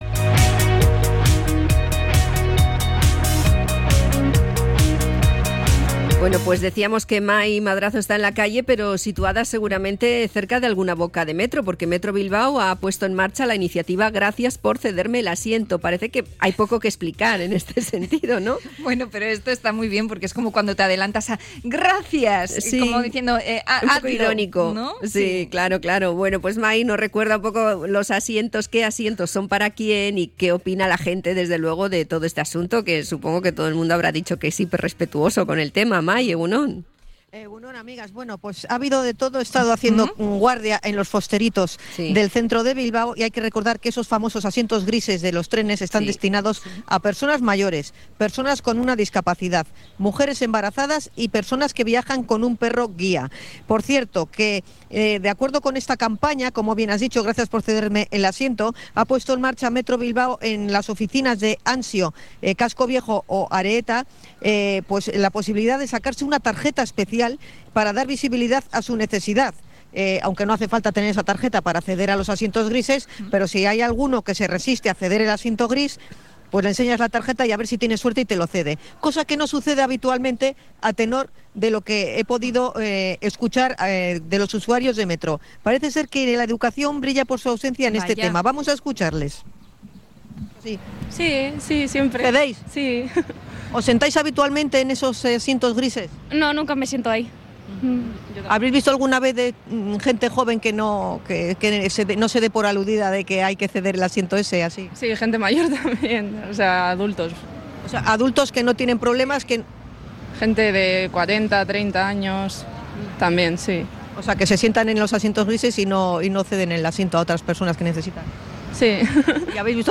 🅱¿Cedes el asiento en el metro o te haces el sueco? Salimos a la calle a comprobarlo
Hablamos con varios usuarios de Metro Bilbao acerca del respeto a las personas que necesitan sentarse
Encuestas en los fosteritos del centro
Hemos estado recabando opiniones a pie de fosterito. Por ejemplo, un joven con muletas ha comentado que ha notado mayor disposición a cederle  el asiento desde que está lesionado.